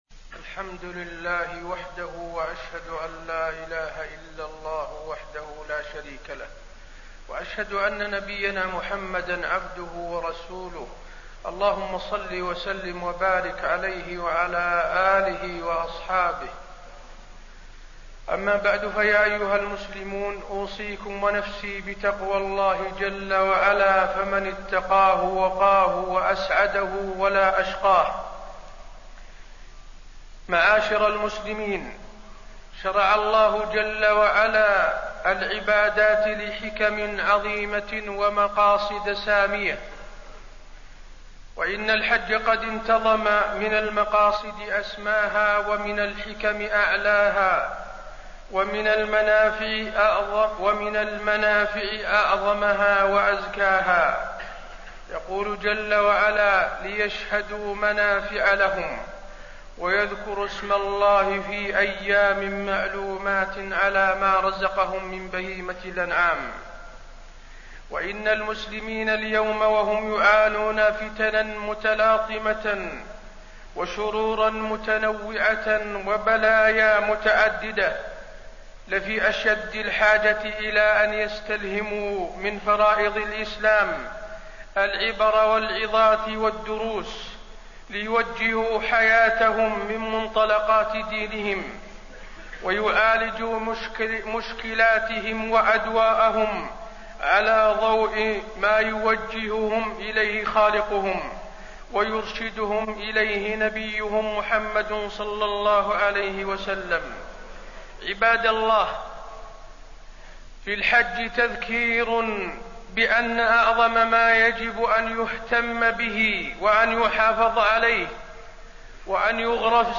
دروس الحج
تاريخ النشر ٢٣ ذو القعدة ١٤٣٢ هـ المكان: المسجد النبوي الشيخ: فضيلة الشيخ د. حسين بن عبدالعزيز آل الشيخ فضيلة الشيخ د. حسين بن عبدالعزيز آل الشيخ دروس الحج